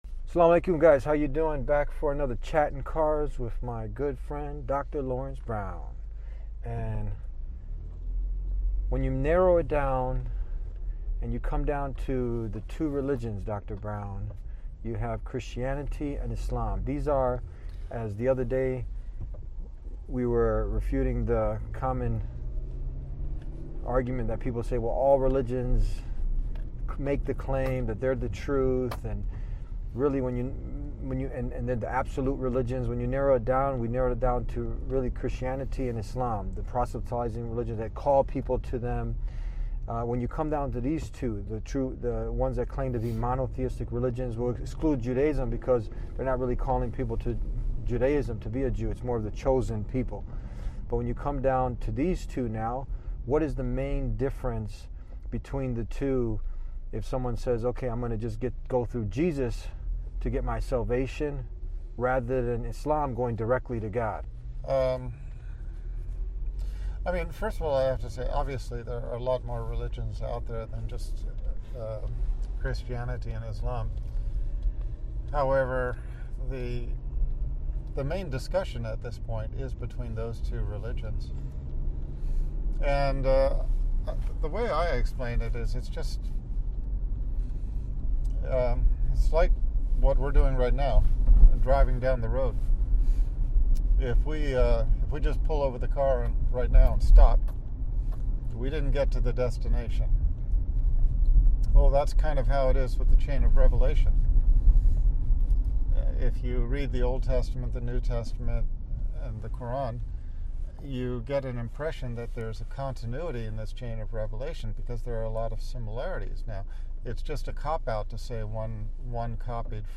Key Takeaways from This Faith Dialogue